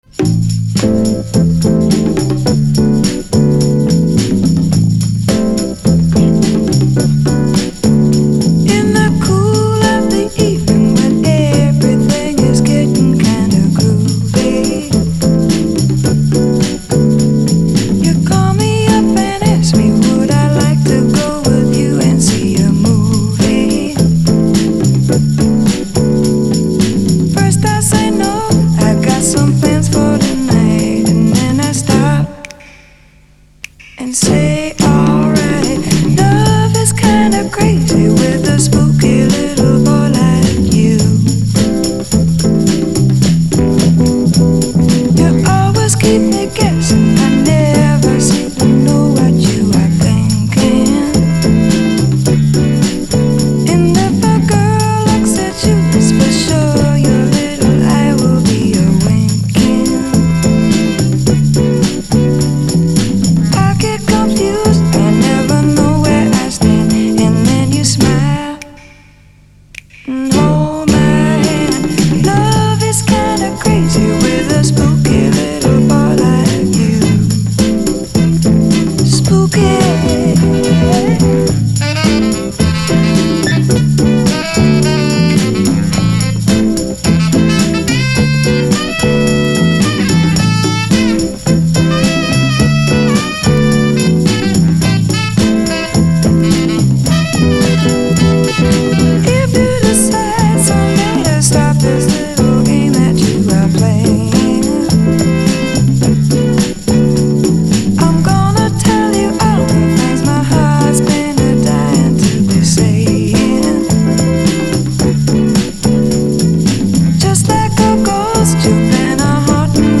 Very nice chill songs